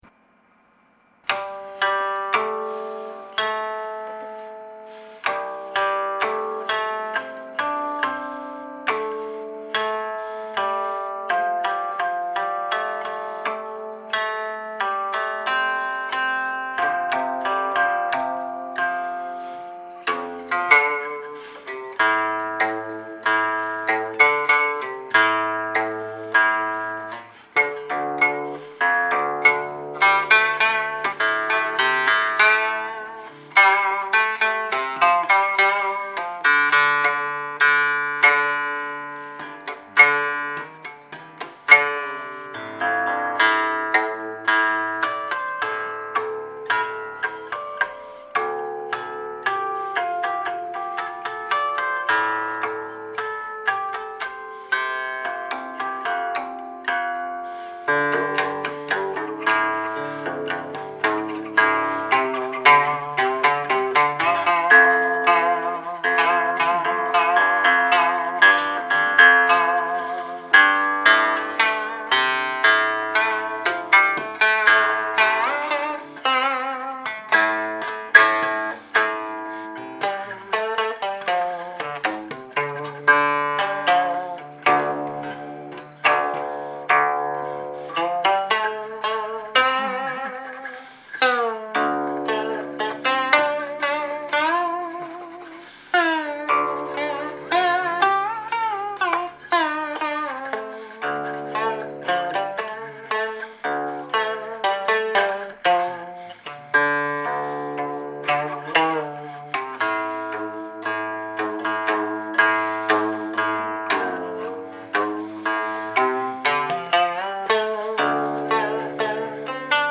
The 1525 Chun Si is in yu mode, its relative scale being 6 1 2 3 5; it could thus be considered as a pentatonic minor scale.
metal strings
It is not clear why he was playing it this way or whether he ever played the full version.